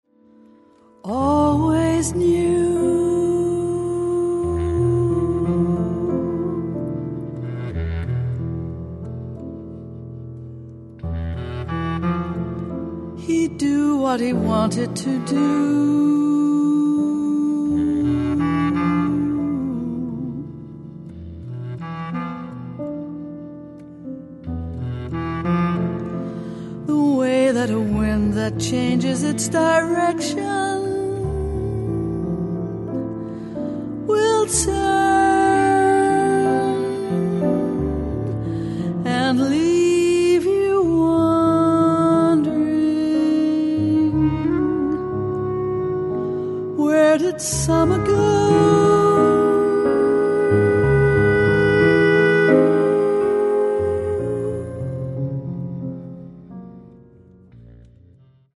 Voice
Piano
Sop. Sax, Bs Clt